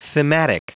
Prononciation du mot thematic en anglais (fichier audio)
thematic.wav